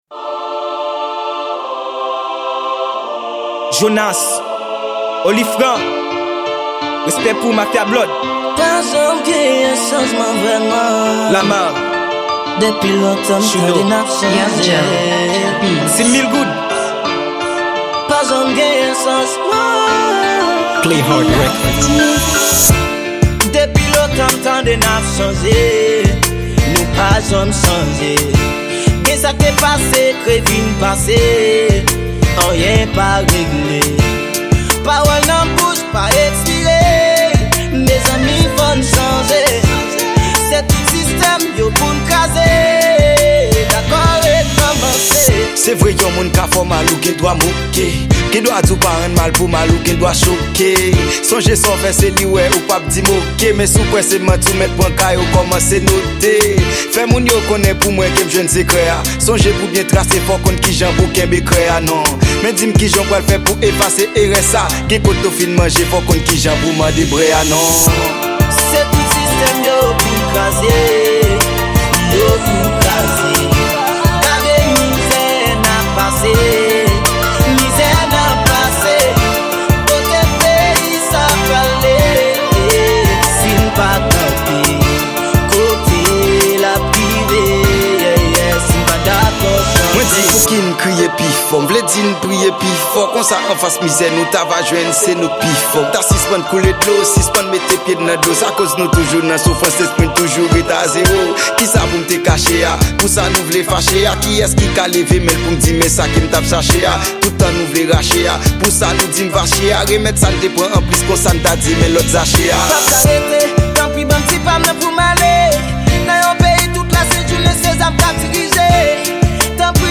Genre: Rao.